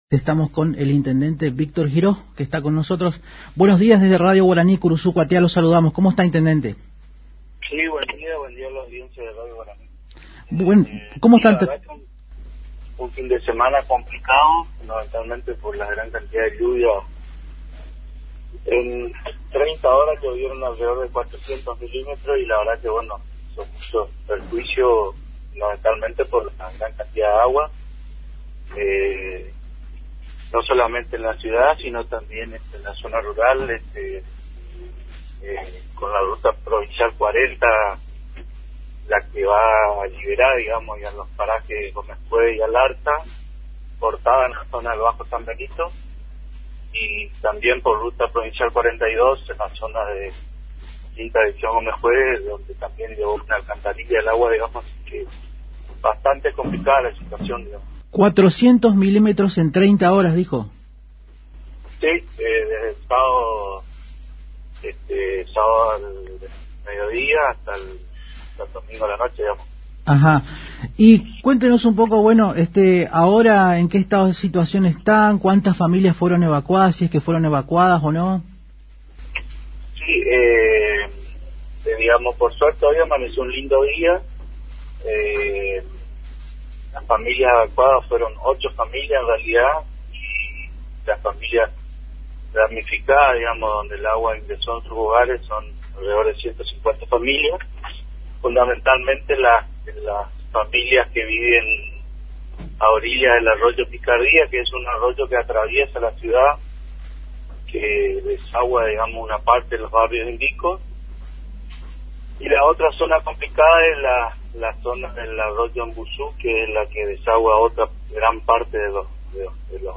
(Audio) El Jefe Comunal de Santo Tomé dialogó con Arriba Ciudad a través de la AM 970 Radio Guarani sobre la situación "complicada" que atraviesa la ciudad en las últimas horas sobre todo desde este fin de semana cuando cayeron nada menos que alrededor de 400 milímetros en 30 horas.